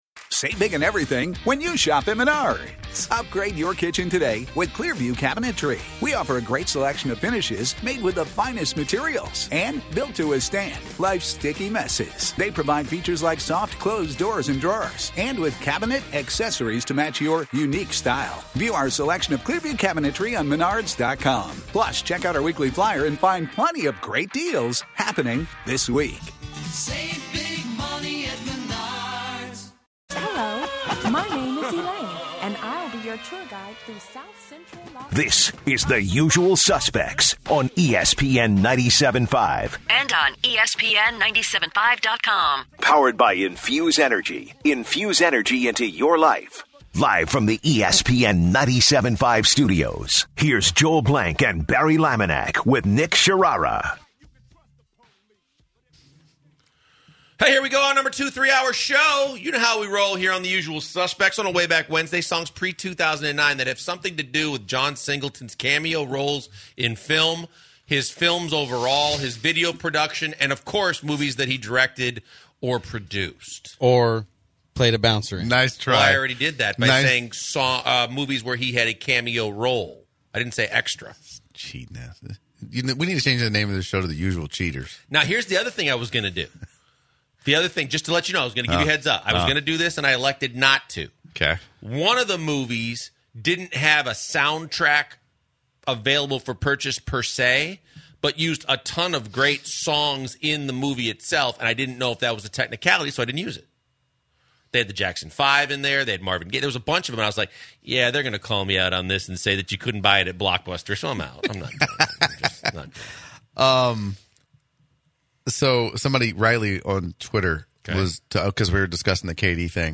The guys start the second hour talking to callers about the Rockets defense, Golden State’s rotation, and the Rockets upcoming home game.